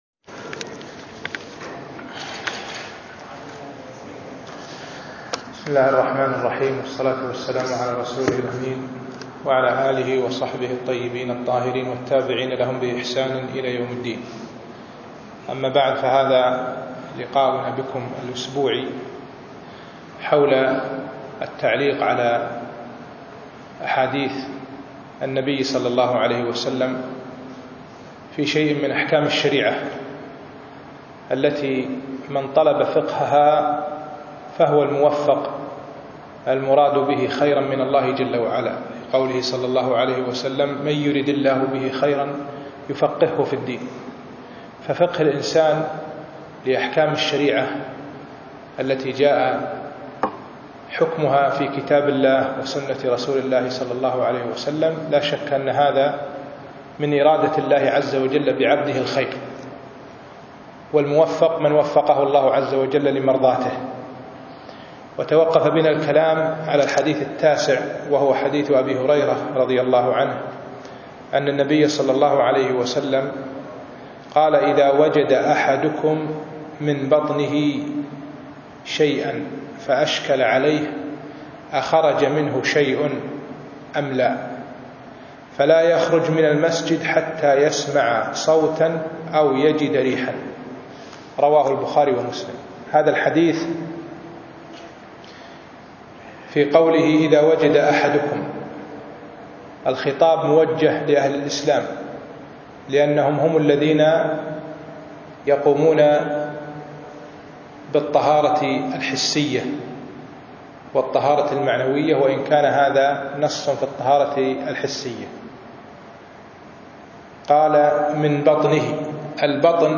الأثنين 6 رجب 1436 الموافق 4 5 2015 بعد المغرب مسجد جلوي العربيد عبدالله المبارك
الدرس الخامس